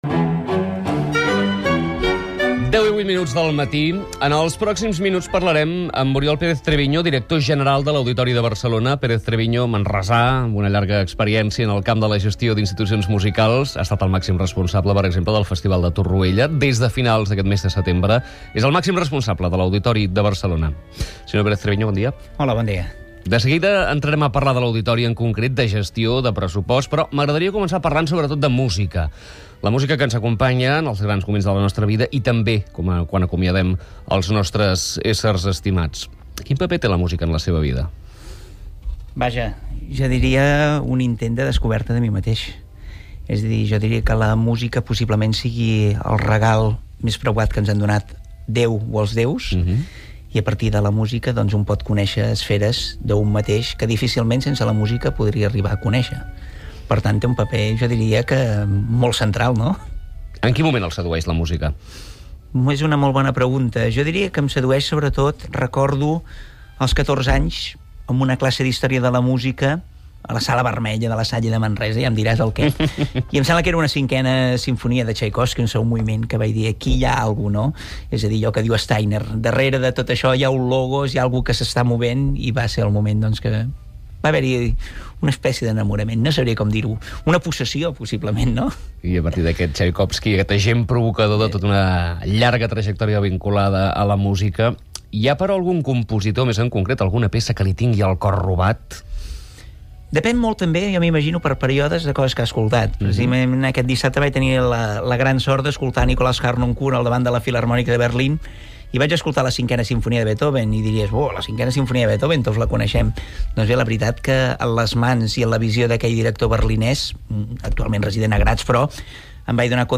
Interessants continguts que us deixo en l’entrevista sencera i que espero que motivi la vostra participació.